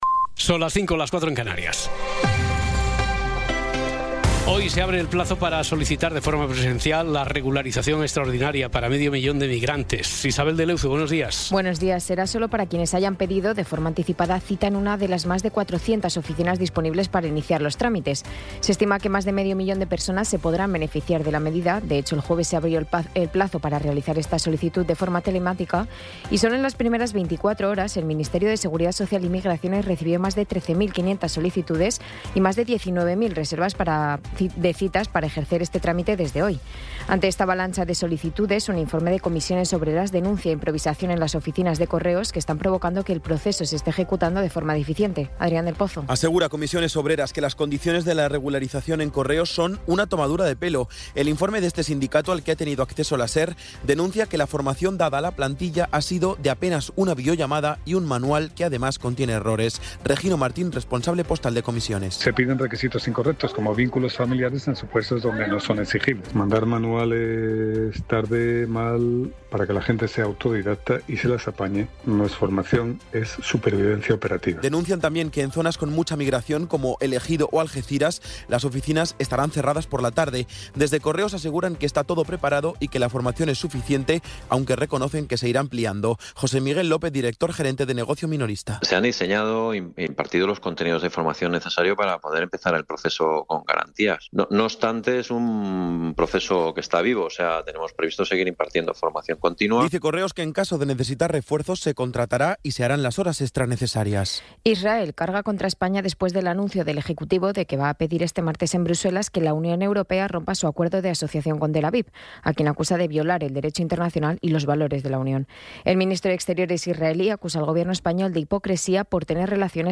Resumen informativo con las noticias más destacadas del 20 de abril de 2026 a las cinco de la mañana.